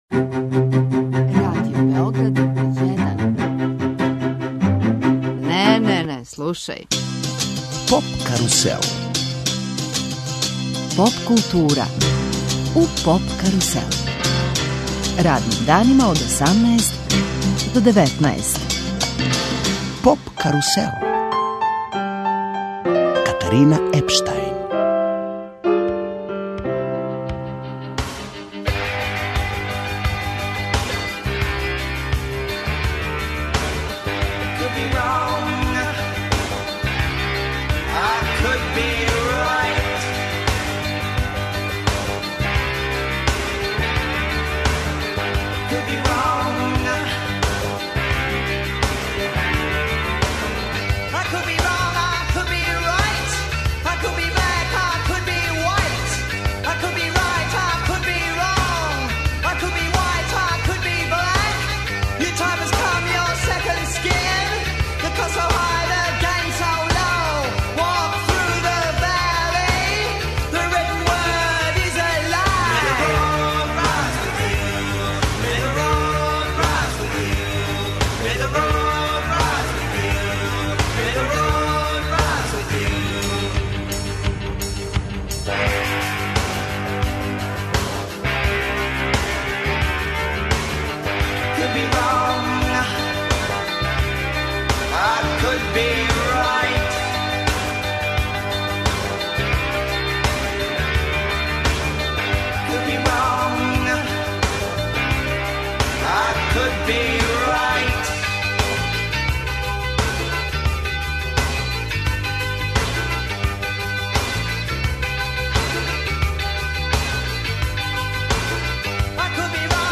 Емитујемо ексклузивни интервју са Џоном Лајдоном.